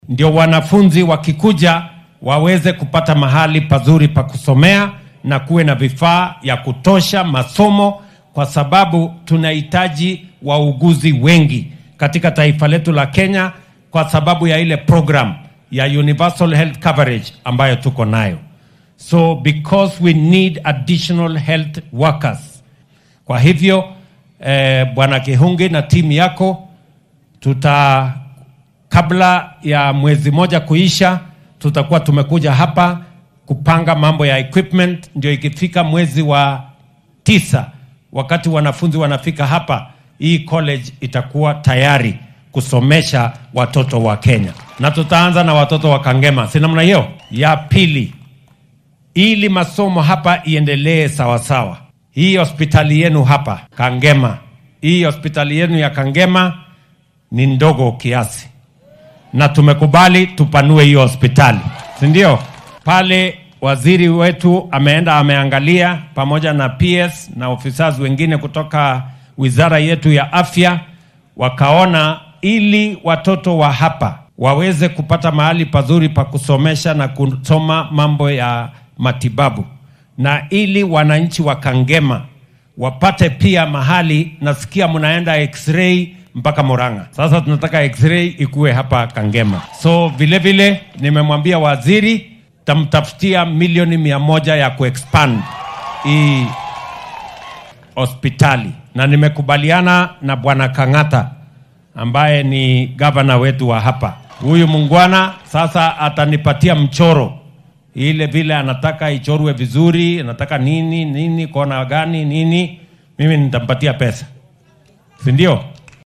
Arrintan ayuu ka hadlay xilli uu si rasmi ah xariga uga jaray machadka culuunta caafimaadka ee magaalada Kangema ee ismaamulka Murang’a. Waxaa uu xusay in dowladdu ay lacag dhan 65 milyan oo shilin u qoondeysay sidii machadkaasi loo qalabeyn lahaa si loo gaars